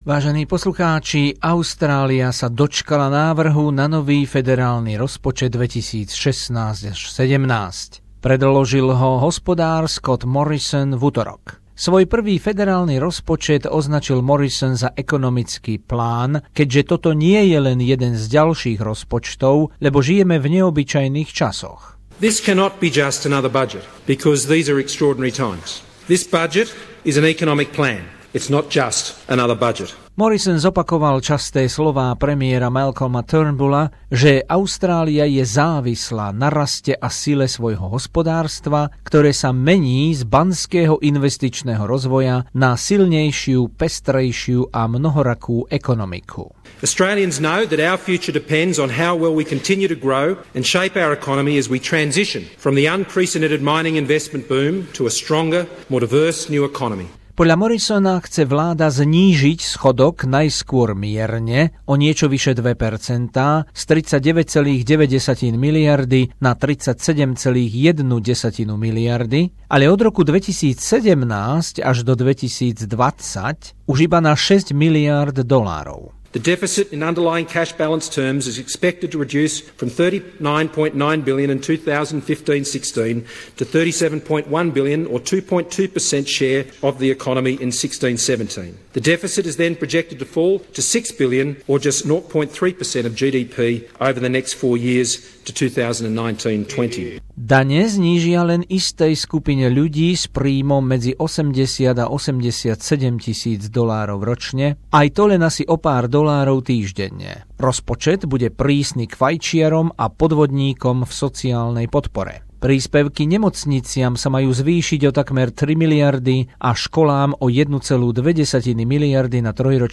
Rozšírená správa z dielne SBS o návrhu nového federálneho rozpočtu na fiškálny rok 2016-17